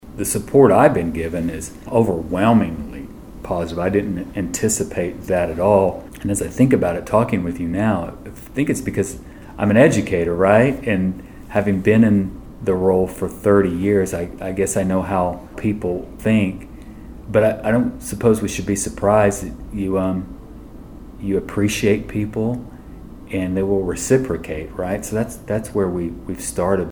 In a sit-down interview with Bartlesville Radio, Fields reflected on his first weeks in office and shared his vision for the future of Oklahoma education.
Lindel Fields on Support 11-14.mp3